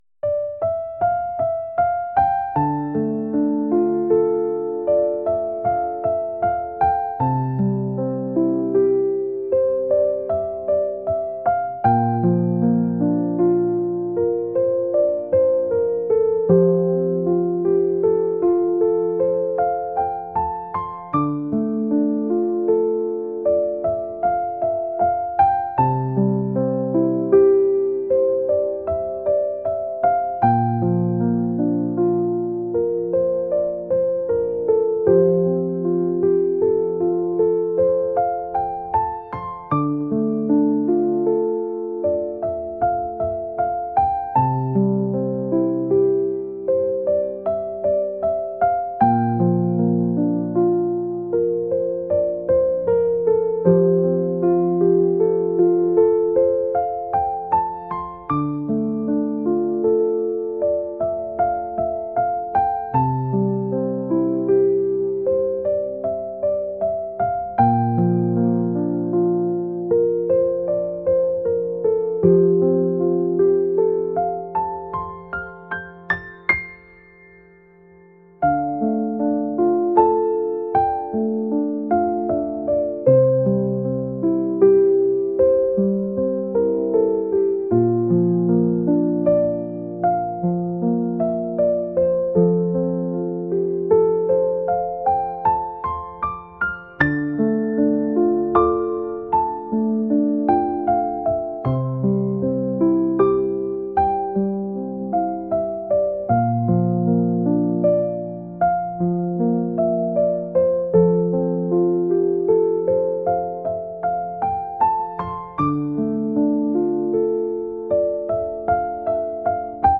acoustic | classical | lofi & chill beats